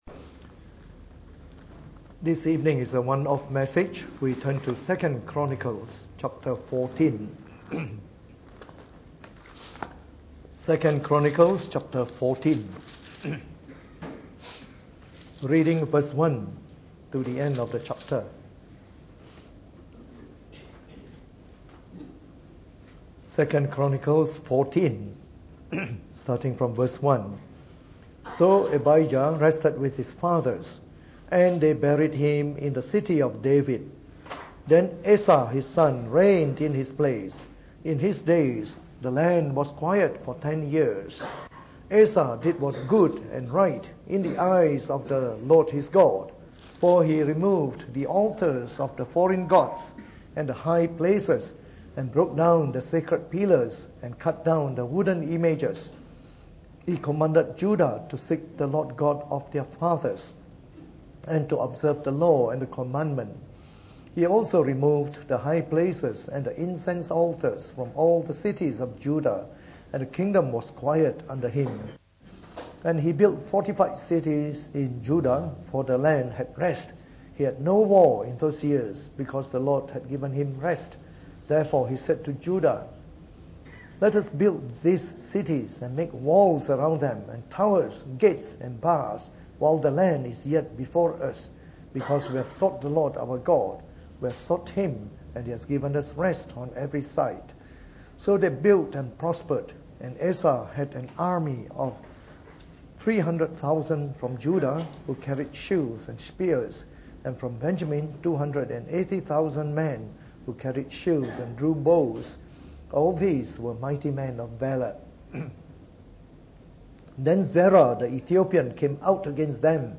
Preached on the 17th of June 2015 during the Bible Study, in anticipation of our next series.